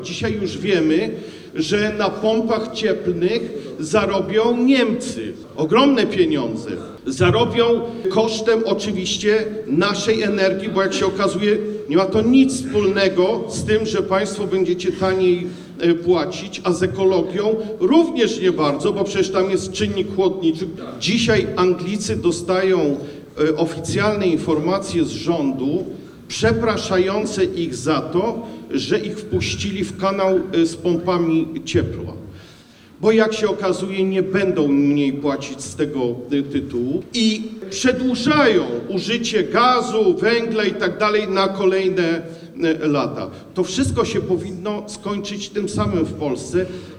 Partyjni działacze Prawa i Sprawiedliwości spotkali się w Choszcznie na zorganizowanej przez frakcję Europejskich Konserwatystów i Reformatorów konferencji dotyczącej bezpieczeństwa energetycznego.
Ten ostatni w swoim wystąpieniu mówił o ochronie środowiska jako elemencie w grze światowych koncernów.